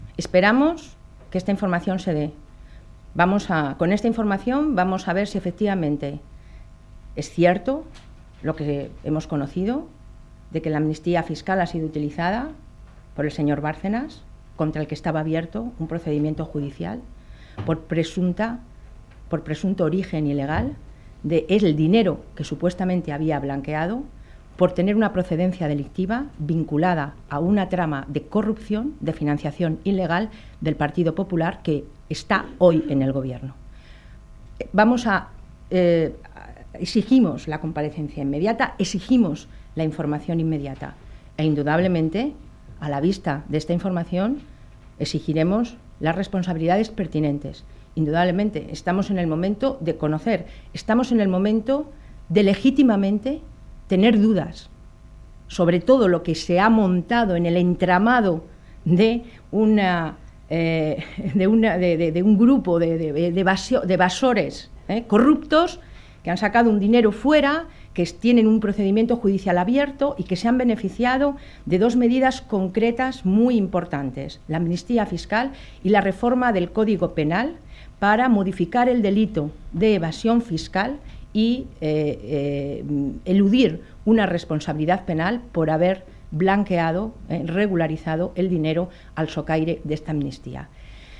Soraya Rodríguez valroa el caso Bárcenas en el Congreso el 17/01/2013